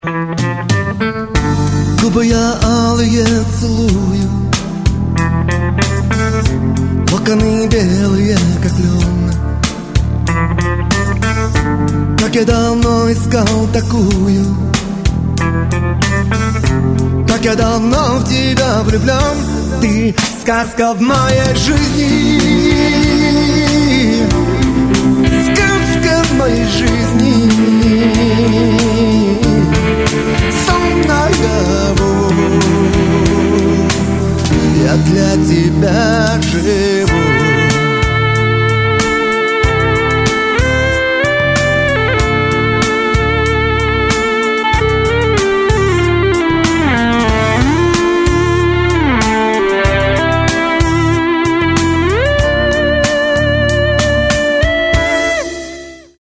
• Качество: 128, Stereo
романтичные
легкий рок
русский рок